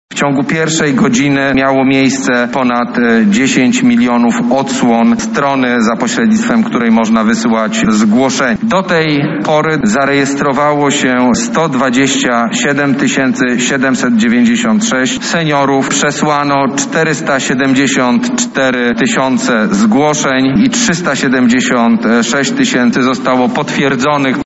-mówi pełnomocnik rządu do spraw szczepień przeciwko COVID-19 Michał Dworczyk